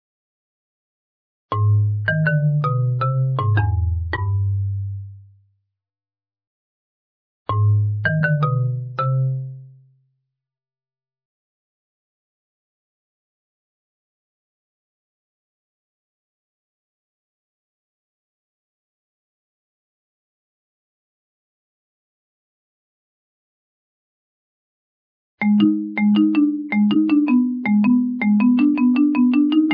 Royalty free marimba music solo, separated by silence.